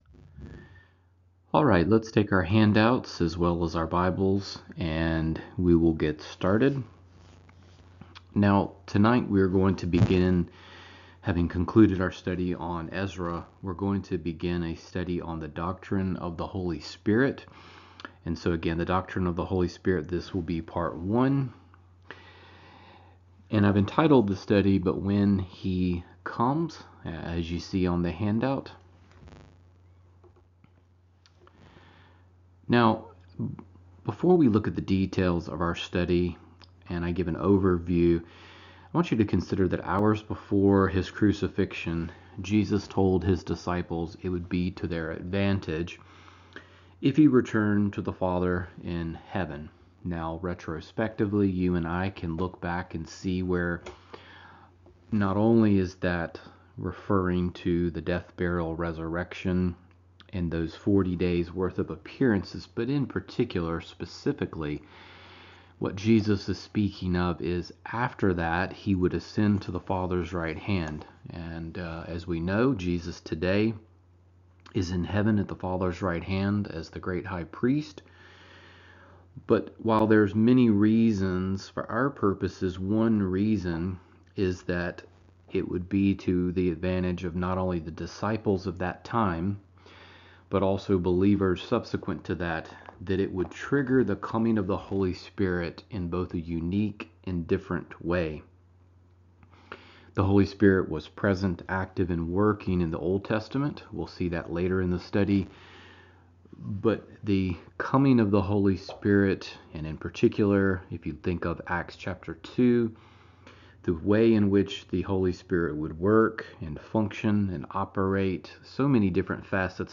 Sermons | Decatur Bible Church